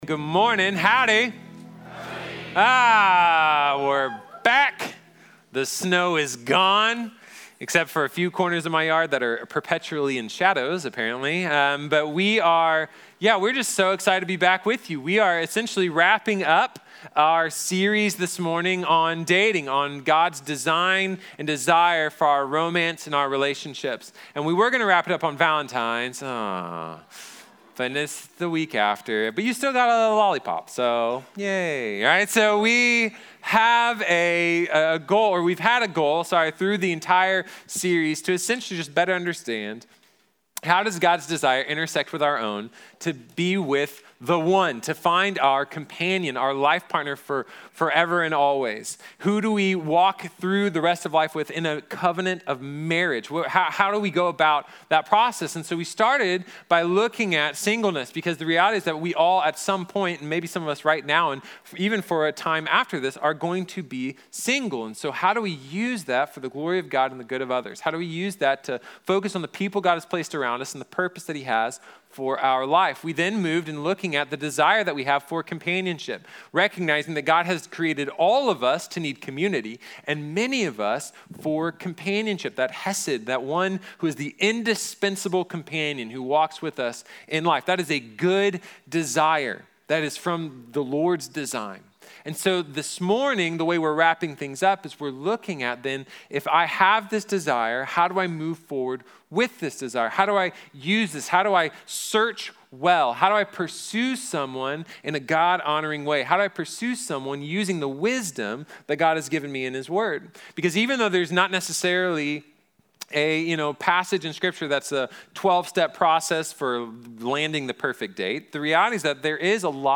Dating | Sermon | Grace Bible Church